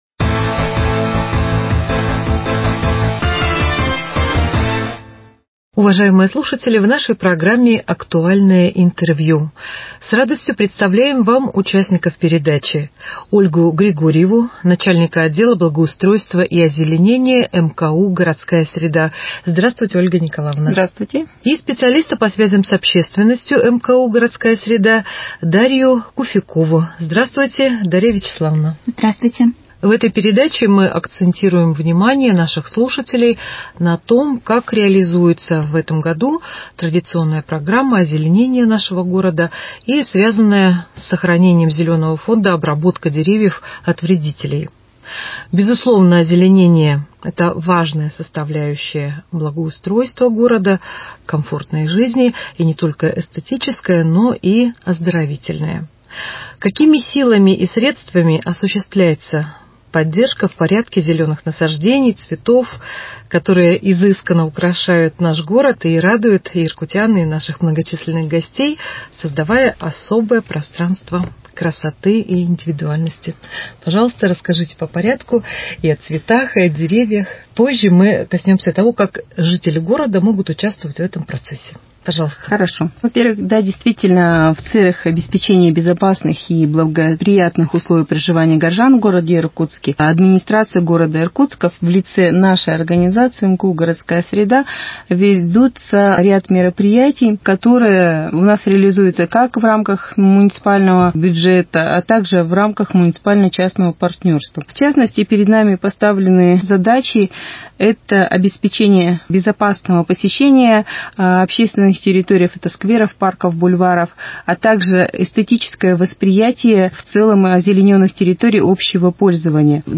Актуальное интервью: Озеленение Иркутска 31.05.2021